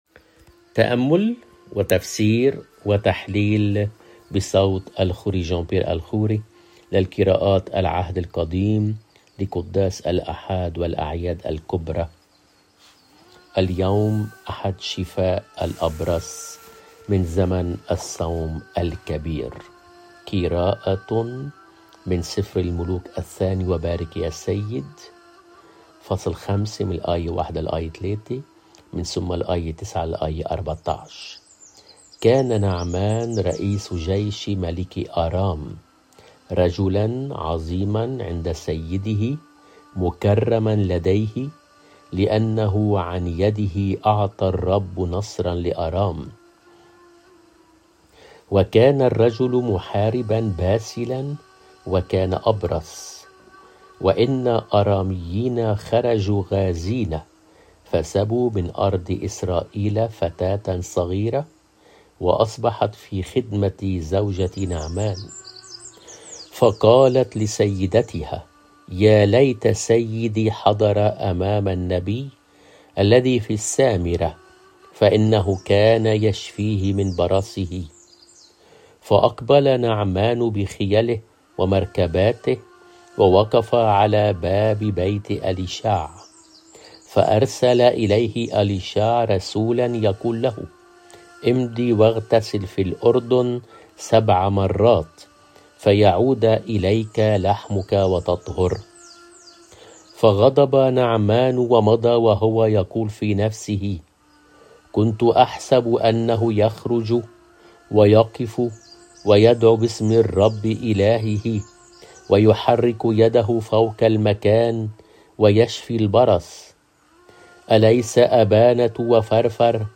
قراءة من العهد القديمقِراءةٌ مِن سِفْرِ الملوك الثاني (٢ ملوك ٥/ ١ - ٣ + ٩-١٤)